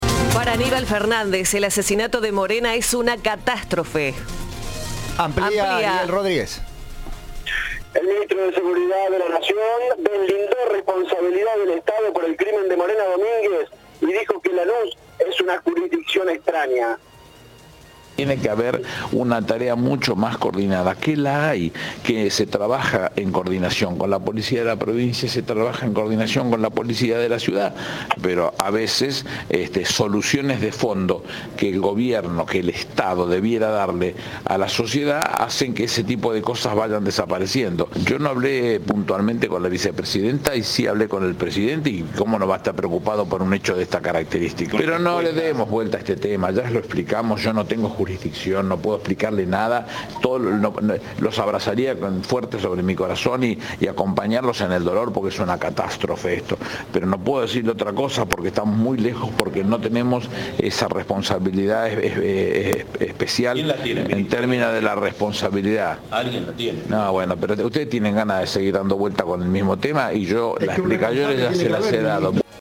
"Me vienen a preguntar por un hecho puntual, en el que no tengo jurisdicción y lo que conozco lamentablemente solo es por los medios", expresó en una rueda de prensa a las puertas del Ministerio de Seguridad, y agregó: "No participamos, no puedo contar de lo que no participo".
Informe